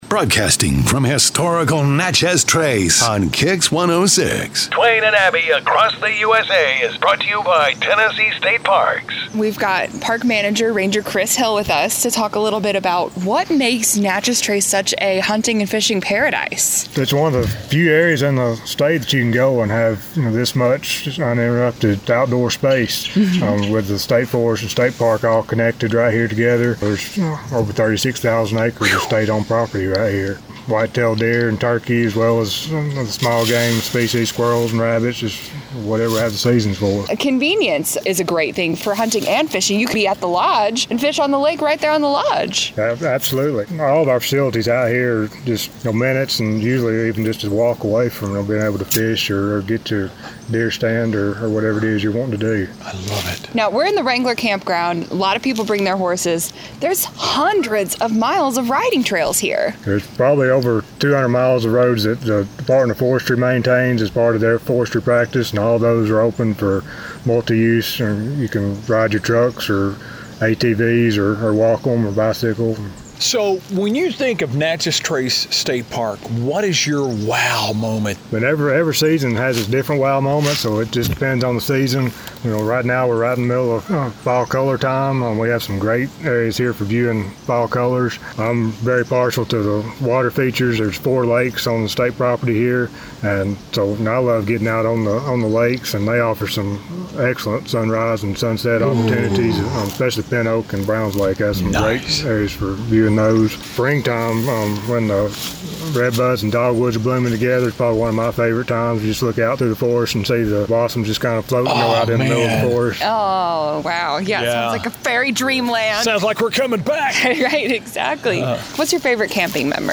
in the Wrangler Campground at Natchez Trace State Park